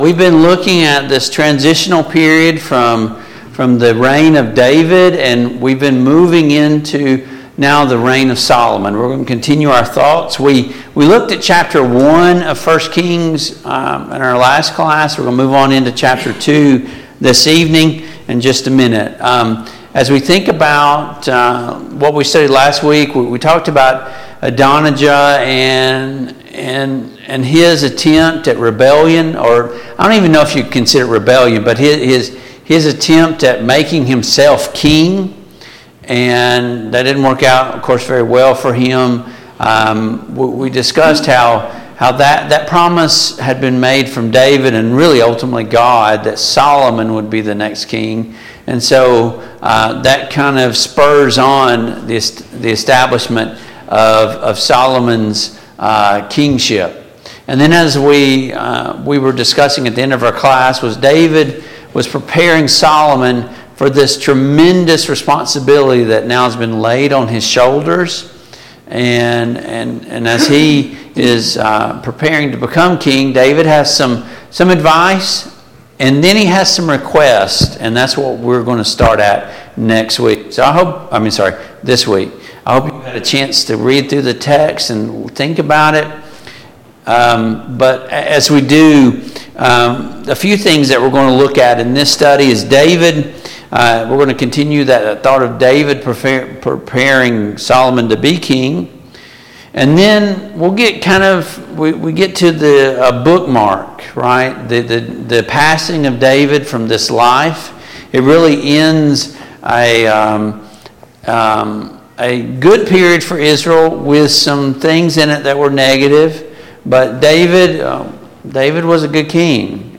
1 Kings 2 Service Type: Mid-Week Bible Study Download Files Notes Topics